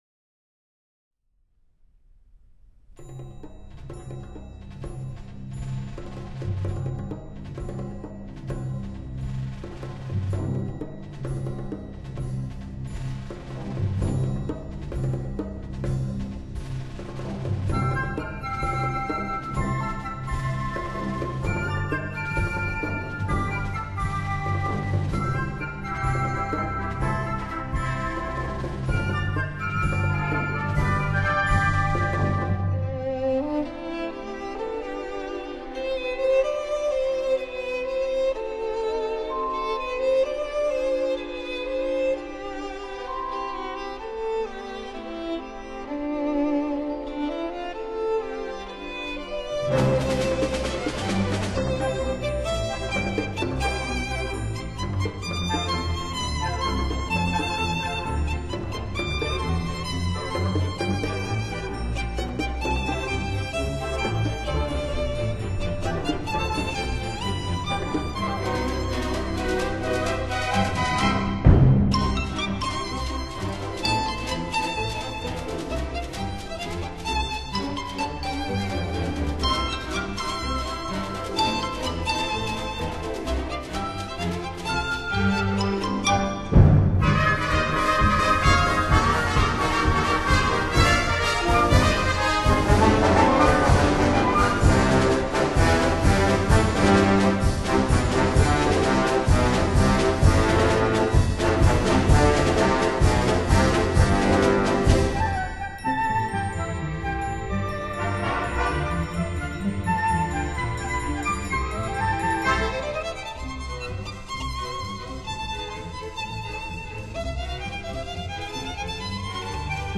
音樂類型：New  Age